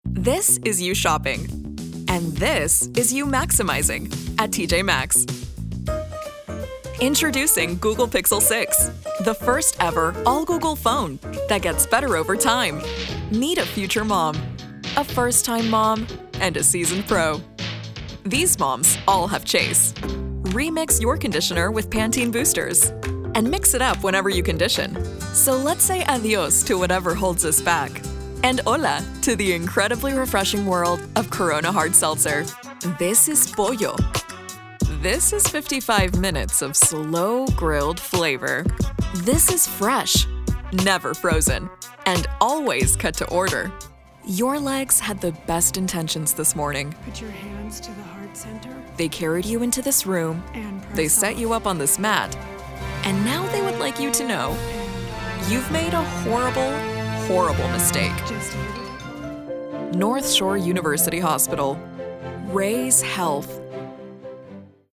announcer, caring, classy, confident, cool, english-showcase, friendly, fuzzy, mother, professional, sincere, soccer mom, sweet